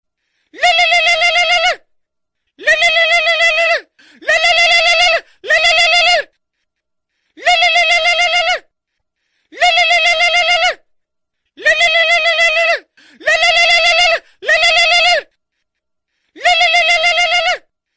Catchy and attractive melody
With repetitive lyrics that create a relaxing feeling